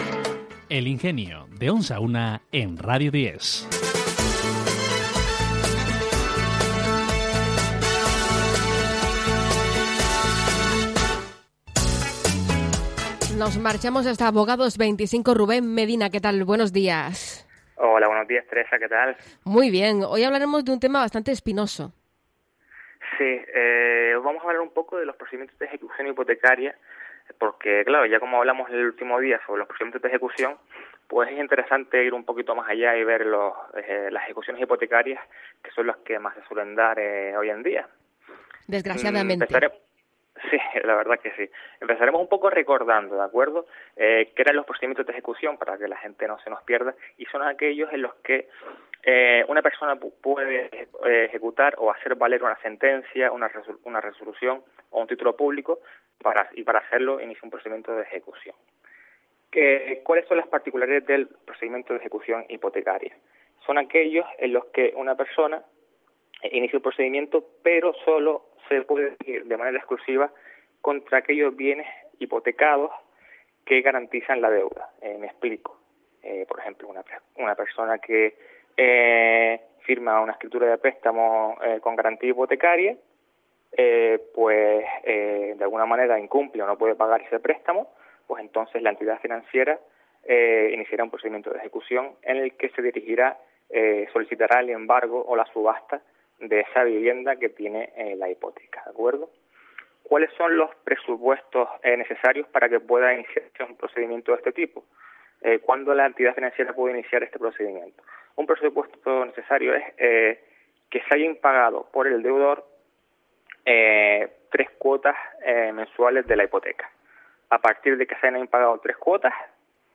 Abogados25 vuelve a colaborar con Radio 10 Ingenio realizando una explicación de los denominados procedimientos hipotecarios
Género: Radio